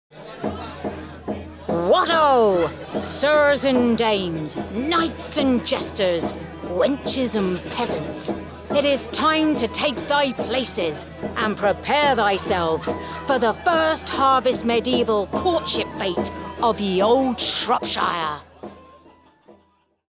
Imitationen
Meine Stimme wird als nahbar, voll, selbstsicher, sanft, selbstbewusst und vertrauenswürdig beschrieben.
Professionelle Gesangskabine mit kabelloser Tastatur und Maus.
Focsurite Scarlett 2i2, D2 Synco-Richtmikrofon und Twisted-Wave-Aufnahmesoftware.